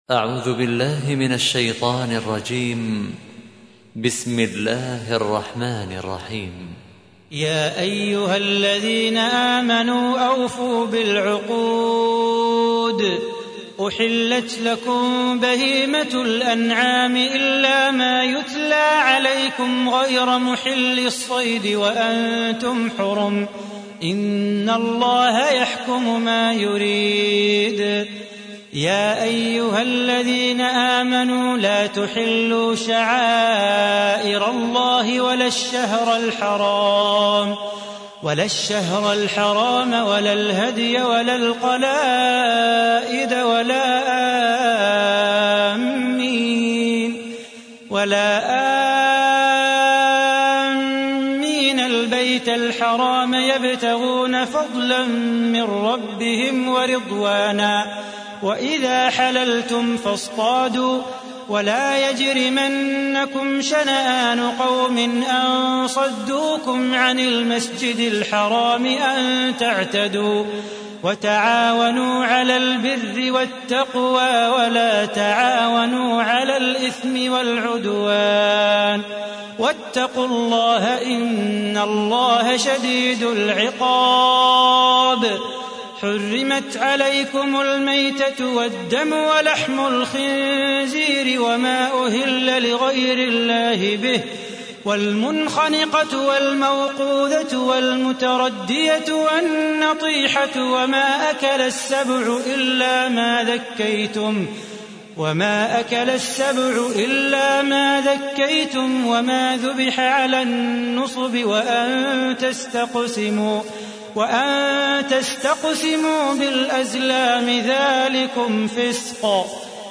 تحميل : 5. سورة المائدة / القارئ صلاح بو خاطر / القرآن الكريم / موقع يا حسين